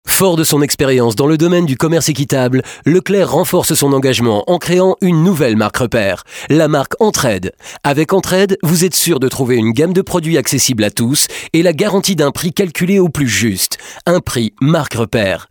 Voix off
Pub Leclerc
- Baryton